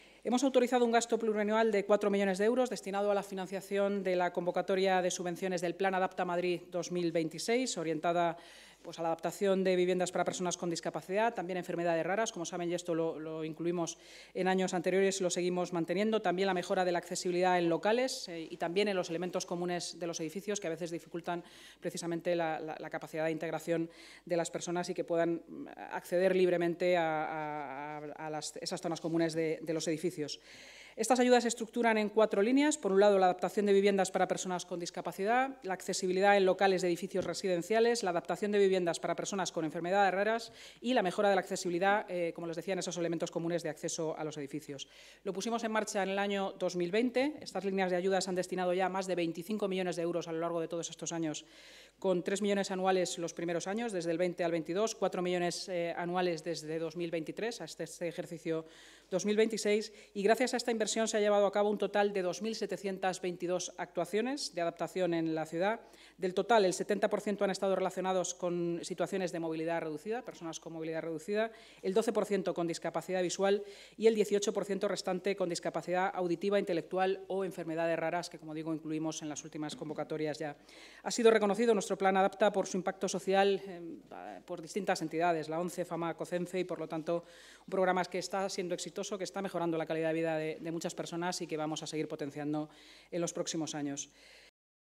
La Junta de Gobierno del Ayuntamiento de Madrid ha autorizado un gasto plurianual de 4 millones de euros destinado a la financiación de la convocatoria pública de subvenciones del Plan Adapta Madrid 2026, orientada a la adaptación de viviendas para personas con discapacidad y enfermedades raras, así como a la mejora de la accesibilidad en locales y elementos comunes de edificios residenciales en la ciudad de Madrid, tal y como ha explicado la vicealcaldesa y portavoz municipal, Inma Sanz, en rueda de prensa.